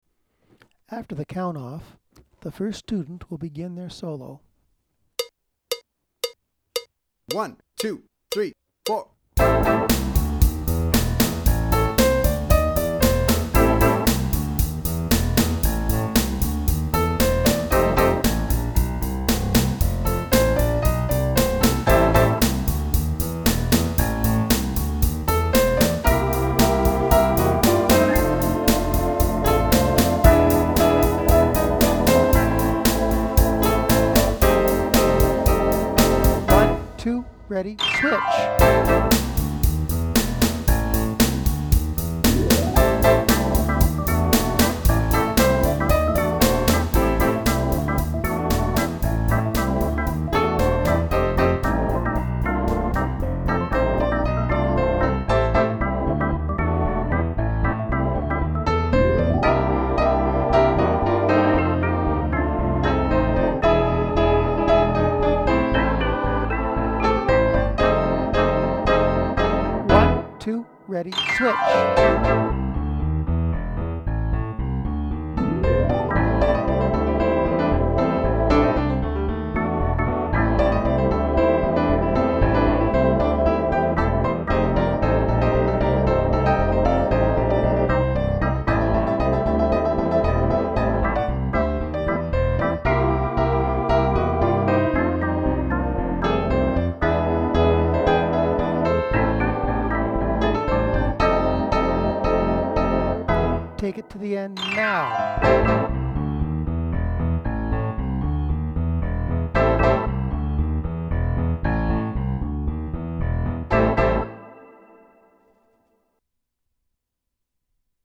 12 Bar A minor Blues Chords for Beginners (Revisited):
Project "Play Along" Options in A minor:
24cBoogieBlueswCountOffs.mp3